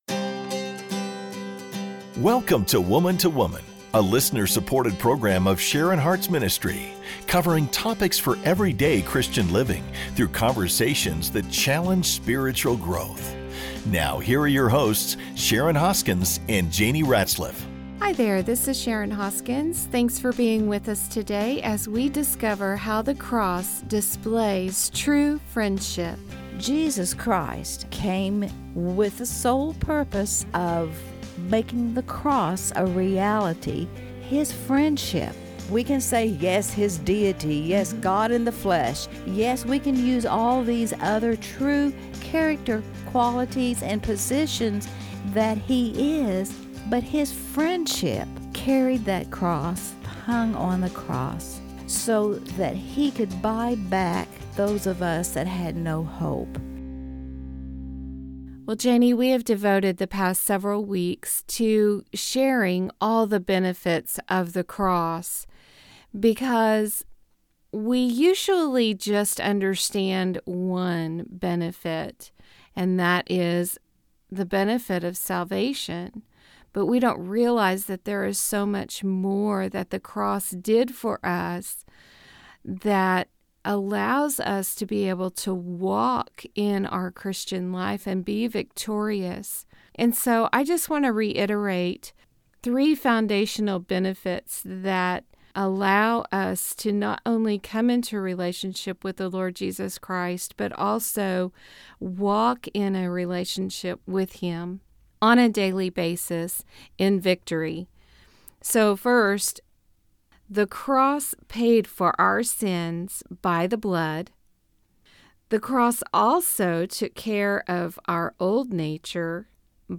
So join in the conversation as we examine the scriptures that tell us how the cross displays true friendship.